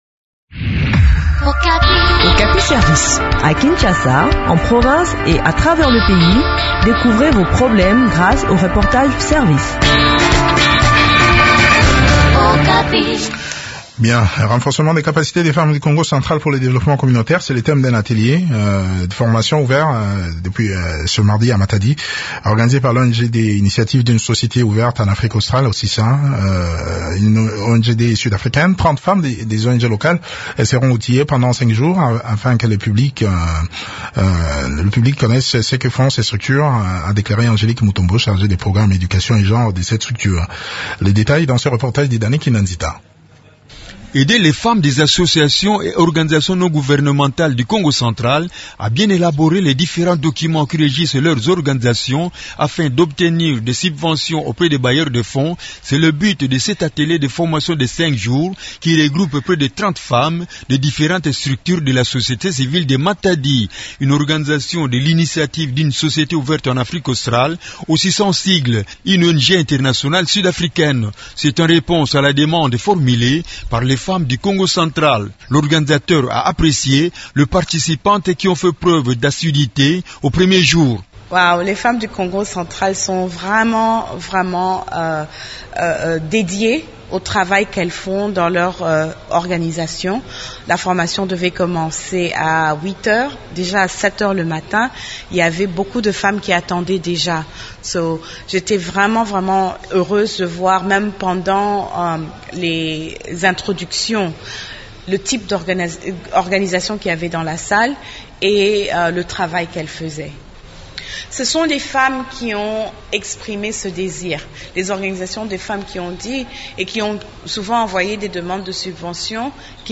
Le point sur le déroulement de cette formation dans cet entretien